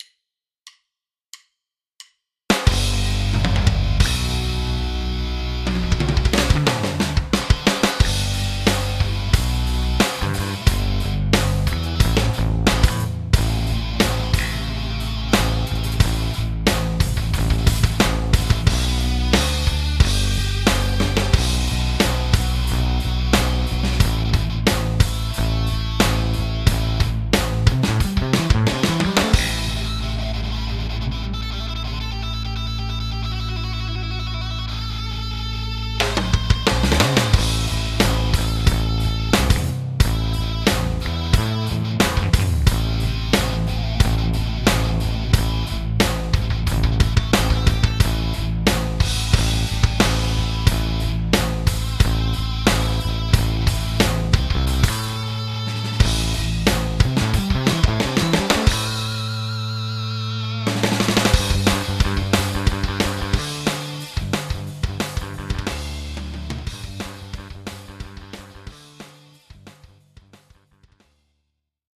Prominy lance une banque de samples dédiée à la Les Paul Custom au format GigaStudio (2 et 3), la LPC Electric Distortion and Clean Guitar.
Voila une premiere démo que j'ai fait rapidement pour m'habituer à la béte :
la partie centrale/solo est plus faible cependant, mais bon, connaissant la banque, tu feras bien mieux (si je puis me permettre ;) ) dès que tu l'auras dans les pattes.
là, en effet, sur certaines parties de ton morceau, ça commence à titiller le hors-phase...